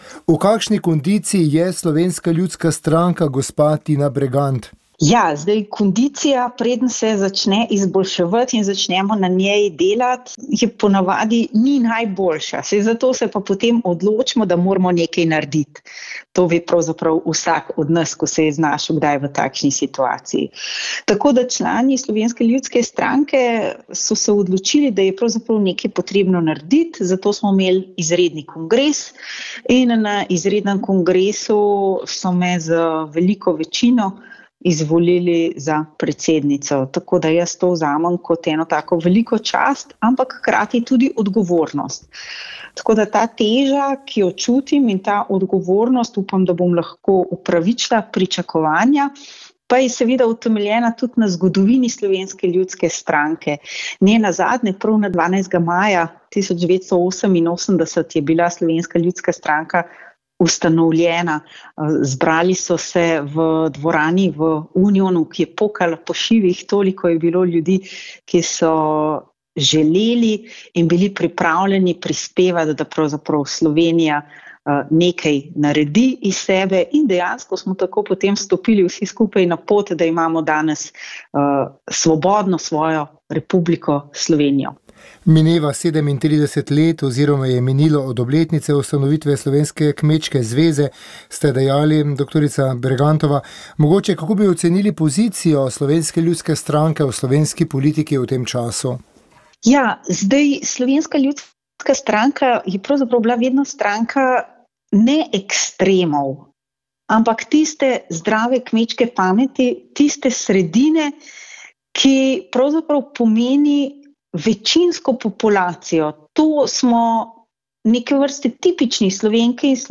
info pogovor eu politika ljudmila novak evropski parlament koronavirus